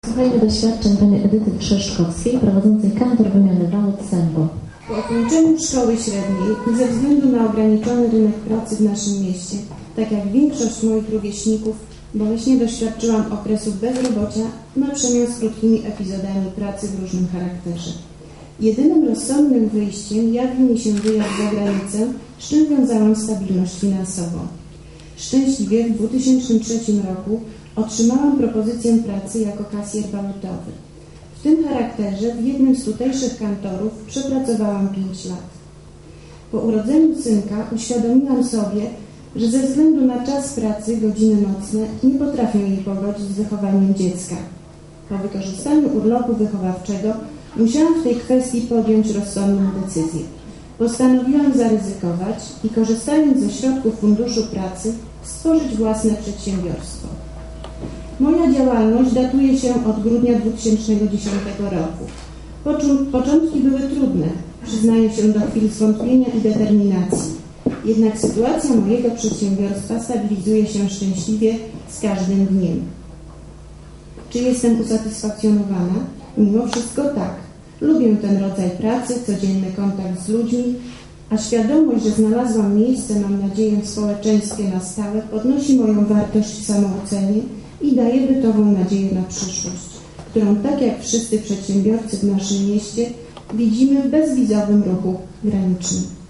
Bardzo ciekawym punktem było odczytanie wypowiedzi przedsiębiorców, którzy uzyskali pomoc z PUP na rozpoczęcie działalności, o ich pomyśle na biznes i jego realizacji.